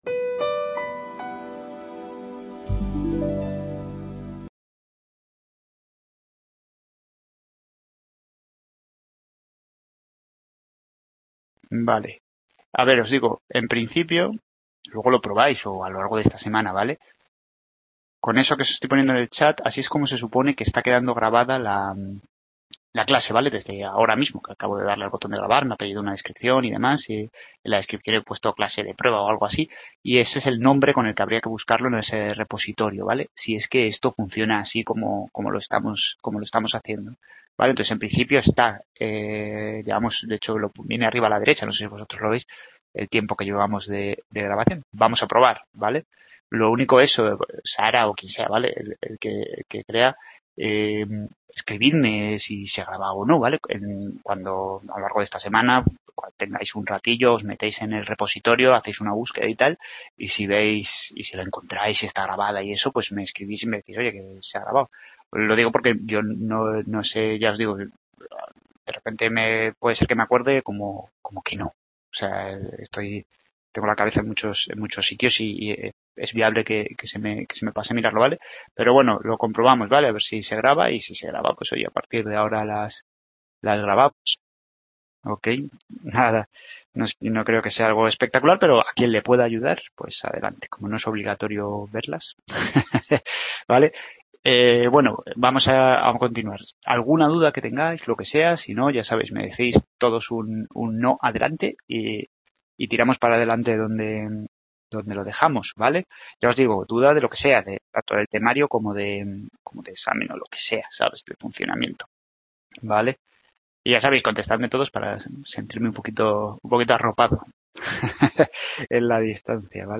Clase de prueba grabada